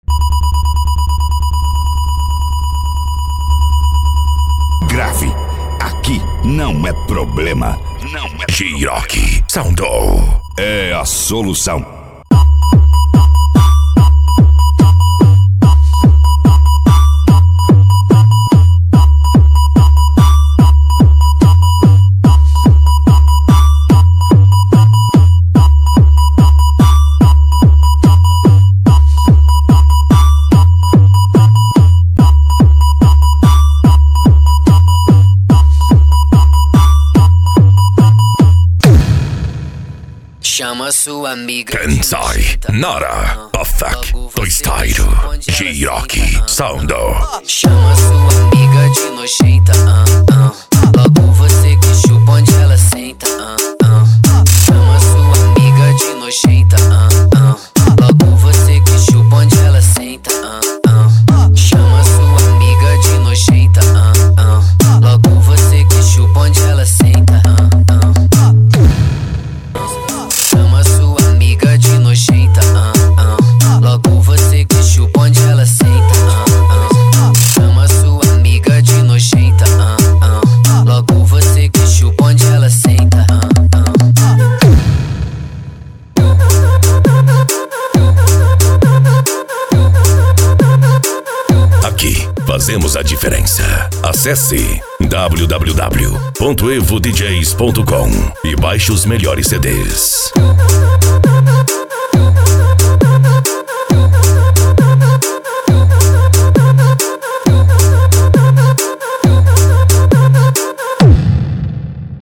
Deep House
Eletronica
Hard Style
PANCADÃO